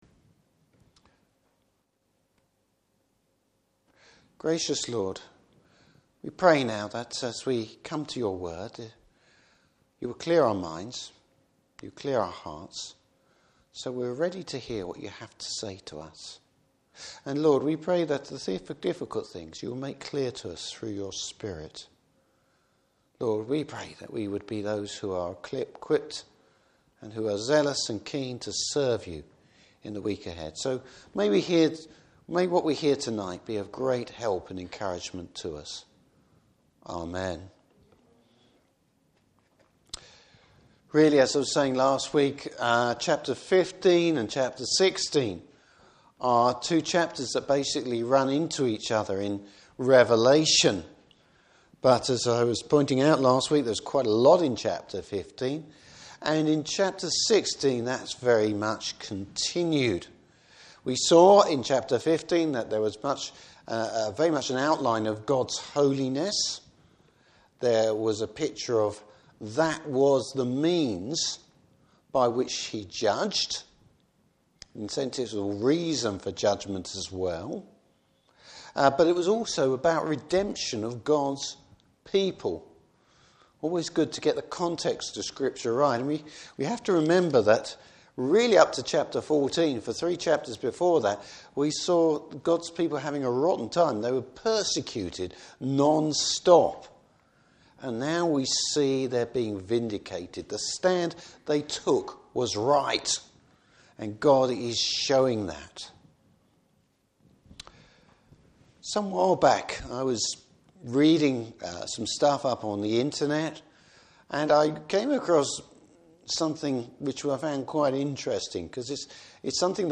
Service Type: Evening Service Bible Text: Revelation 16.